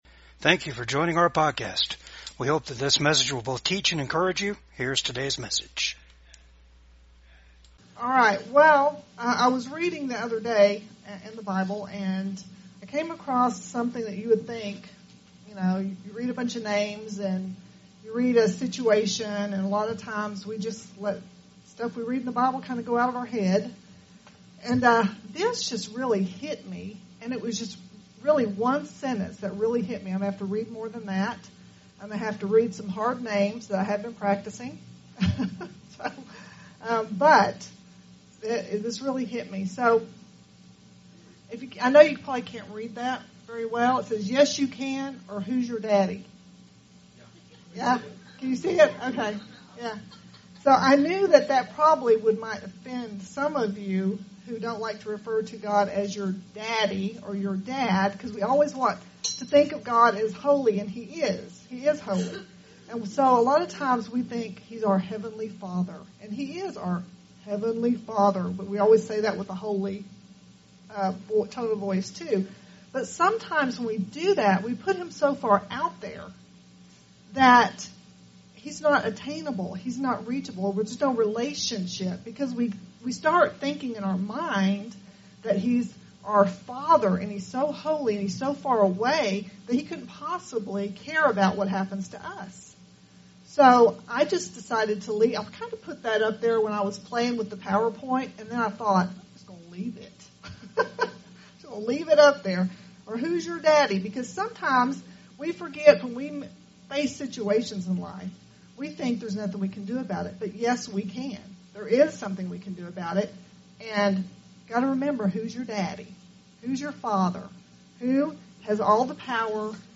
Jeremiah 38:1-5 Service Type: VCAG WEDNESDAY SERVICE 1.Realize you have power and authority. 2.Learn what that authority is. 3.